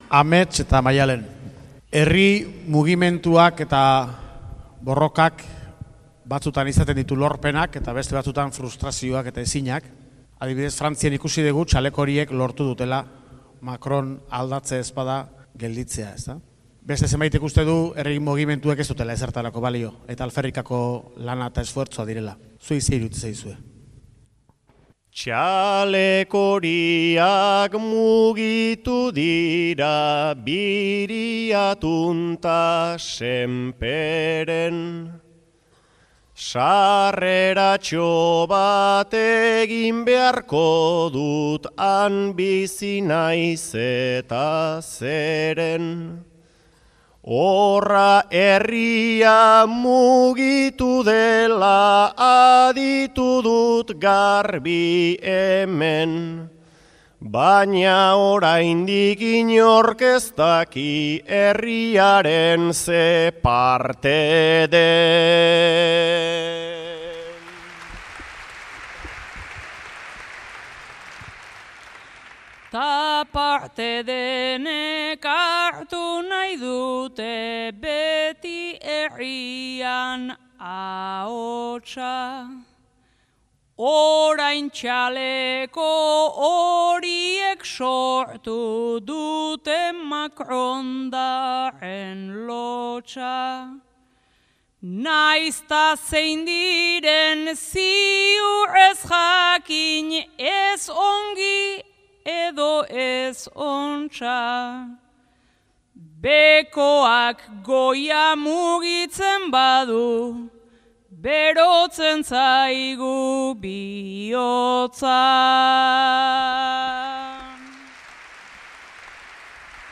Amets Arzallus eta Maialen Lujanbio bertsotan